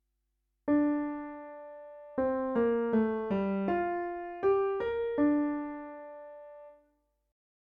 Ex 6 Example 6: D Minor